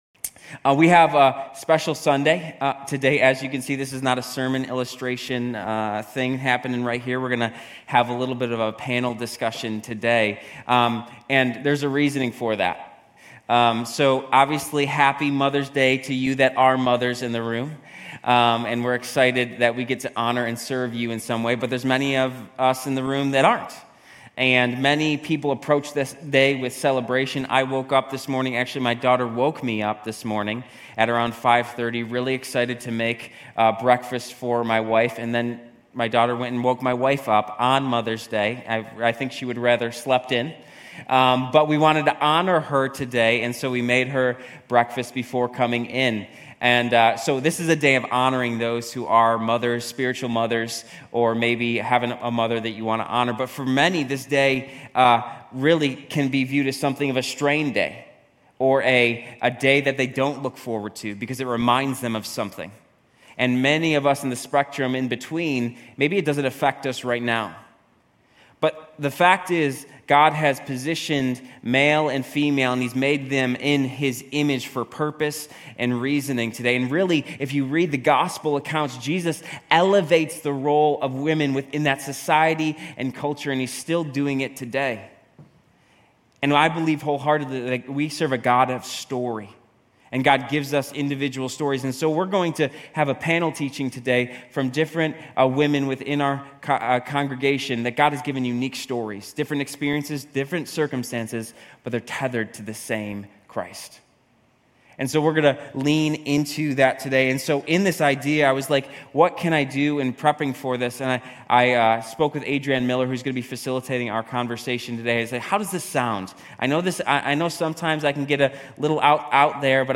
Grace Community Church University Blvd Campus Sermons MOTHER'S DAY May 13 2024 | 00:33:58 1x 00:00 / 00:33:58 Subscribe Share RSS Feed Share Link Embed